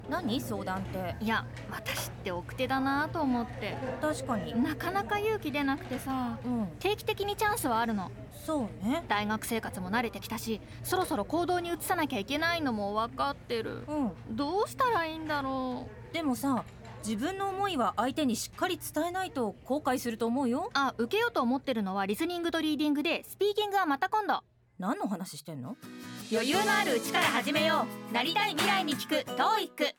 ”奥手”という悩みから恋愛の話かと思いきや、実は「TOEICを受けるかどうかの悩み」だったという意外性のある展開で、印象に残るストーリーを構成。親しみの持てる学生同士の自然な会話を通して、TOEICの受験へのハードルを下げる工夫をしています。